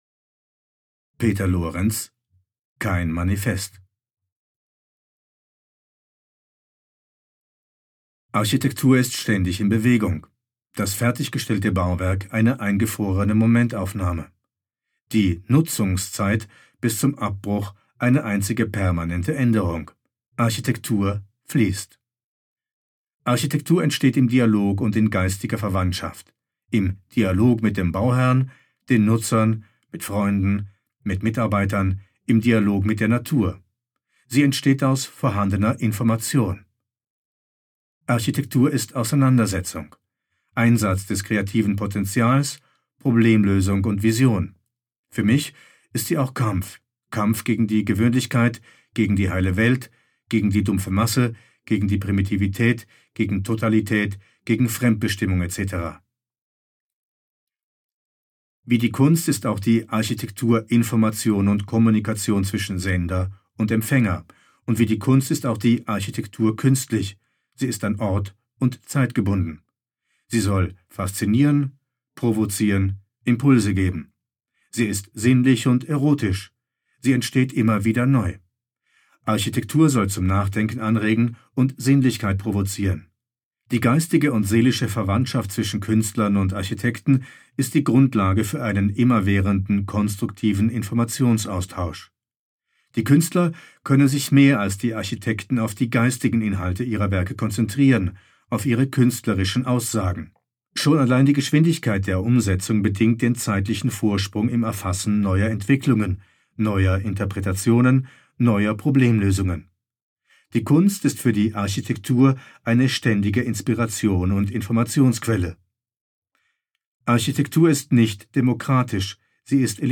Das anlässlich der Eröffnung von aut im Adambräu erscheinende Lesebuch „reprint“ war die Basis für 11 Hörstationen in der 2005 gezeigten Eröffnungsausstellung vermessungen.